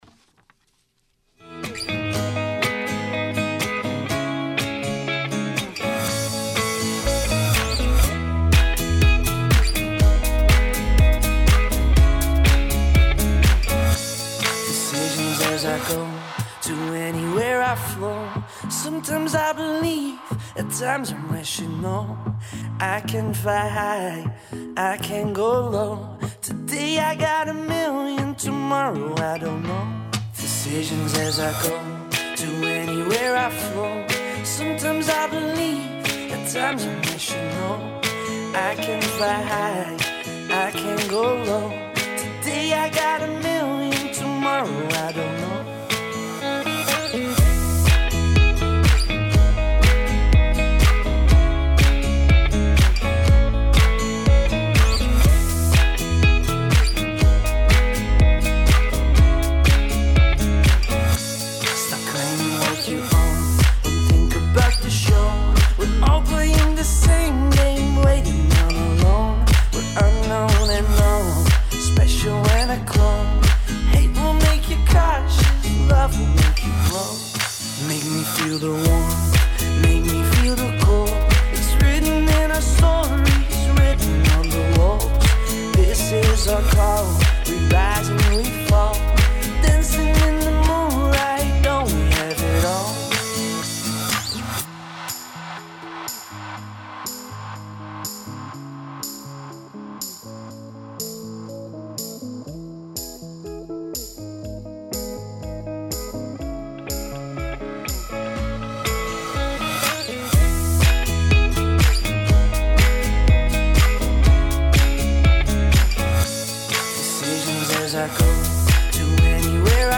The song at the beginning of this radio movie review as well at the end are in the soundtrack of this movie - one backgrounding the happier moments and the other for the deeper moments.